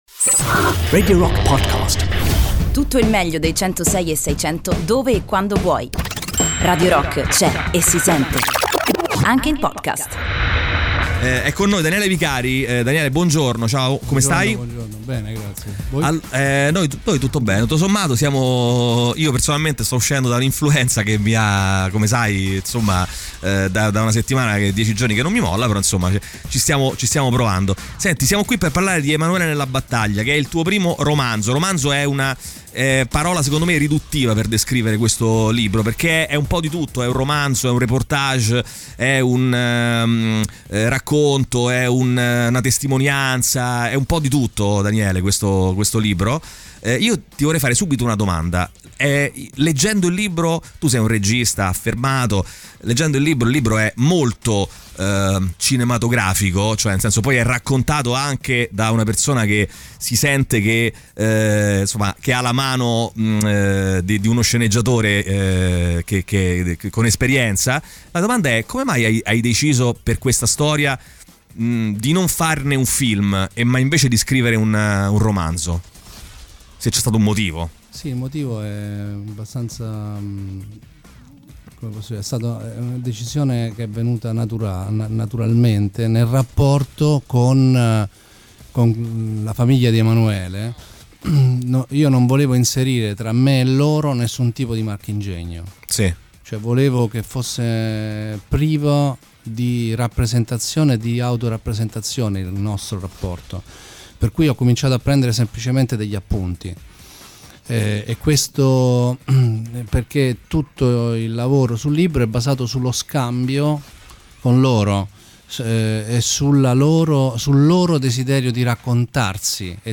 "Intervista": Daniele Vicari (15-10-19)
Ospite nei nostri studi il regista Daniele Vicari per presentare il suo primo romanzo "Emanuele nella battaglia" Enaudi editore